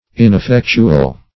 Ineffectual \In`ef*fec"tu*al\ (?; 135), a.